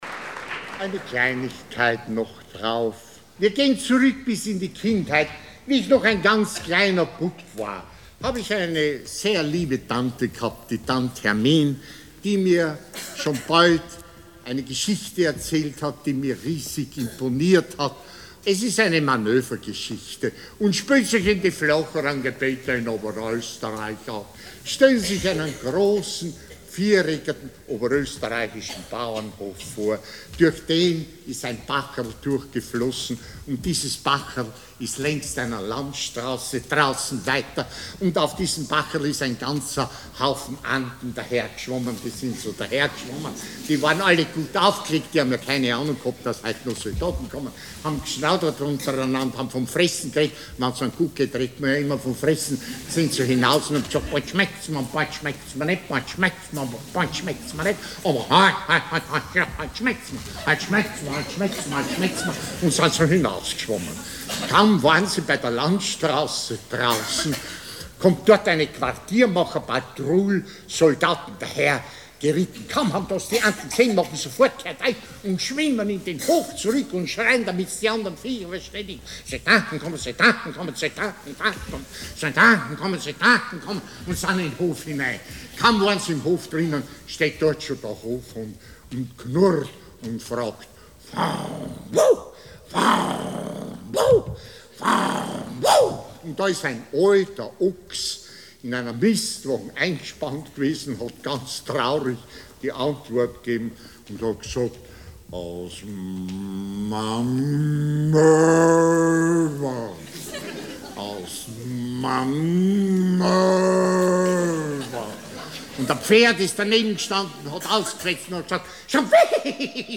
In seinem „Manöwer“ bringt es Richard Eybner fertig, aus der Umformung der Worte in die Lautensprache der Tiere, nicht nur deren eigentümliche „Sprache“ nachzuahmen, sondern zugleich auch das ganze Flair der Monarchie und ihrem Militär als Hör-Bild einzufangen. Ein köstlicher Spaß, ein herrlicher Einfall und ein Kabinettstückerl der Vortragskunst.